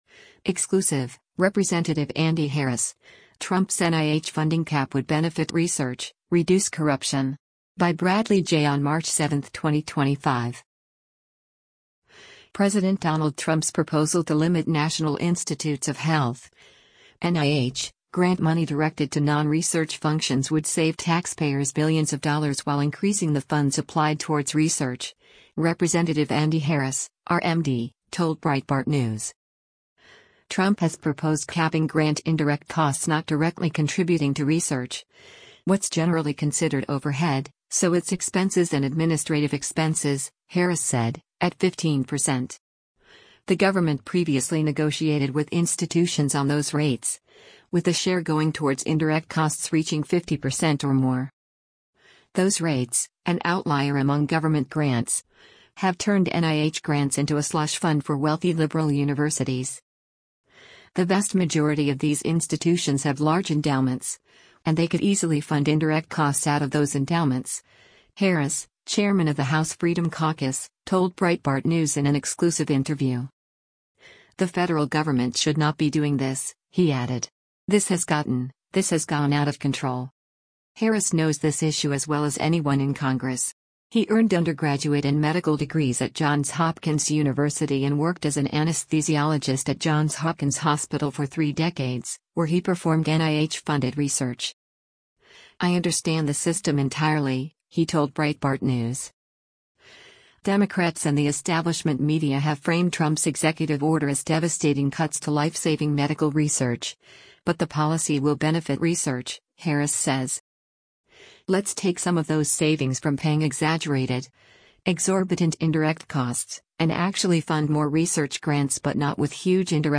“The vast majority of these institutions have large endowments, and they could easily fund indirect costs out of those endowments,” Harris, chairman of the House Freedom Caucus, told Breitbart News in an exclusive interview.